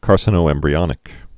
(kärsə-nō-ĕmbrē-ŏnĭk)